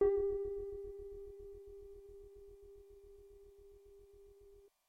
标签： MIDI-速度-16 FSharp4 MIDI音符-67 挡泥板-色度北极星 合成器 单票据 多重采样
声道立体声